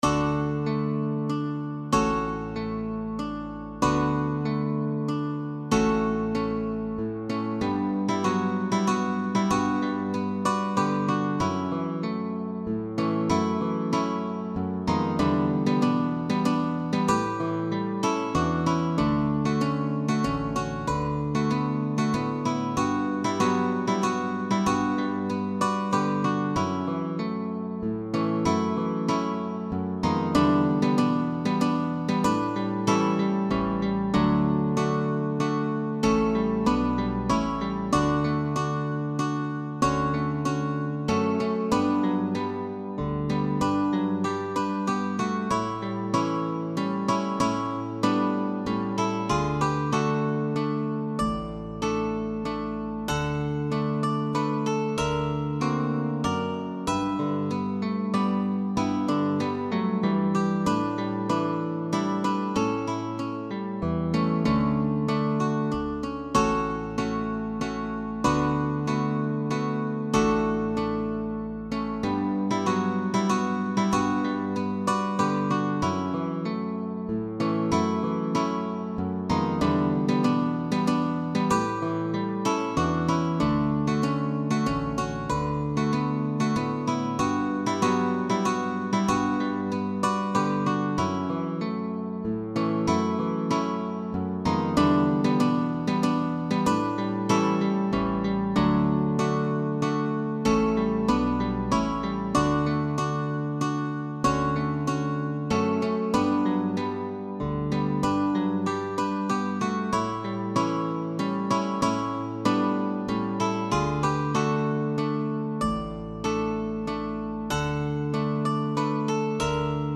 Guitar trio sheetmusic.
GUITAR TRIO
Tags: Pop Songs XX Century